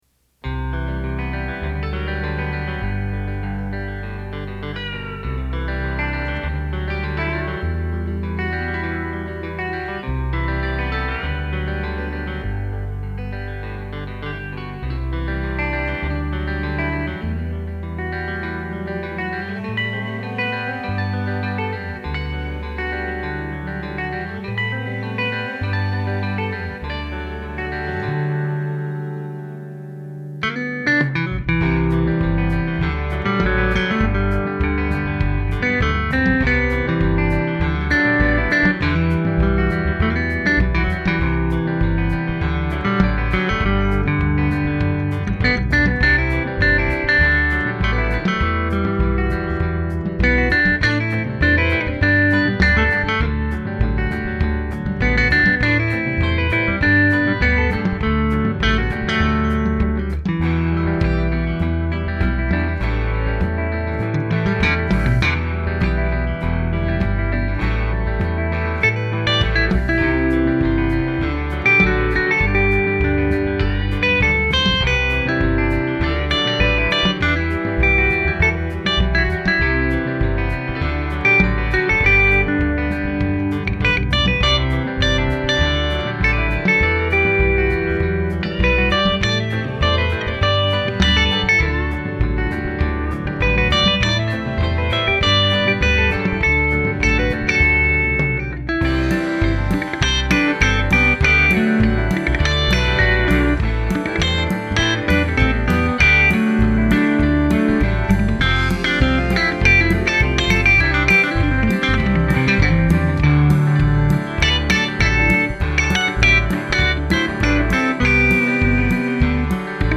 Tele-style electric guitar for Kontakt
Audio Demos